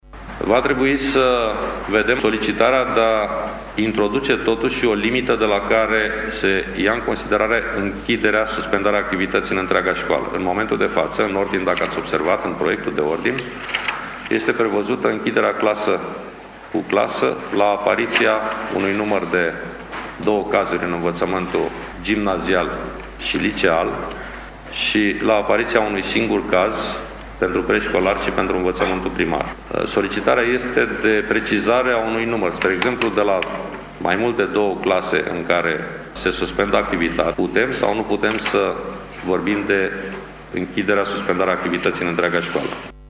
Ministrul Educației și Cercetării, Sorin Cîmpeanu, a precizat azi în videoconfernița cu inspectorii școlari că adeverintele medicale eliberate pe primul semestru rămân valabile, în contextul redeschiderii școlilor pe data de 8 februarie.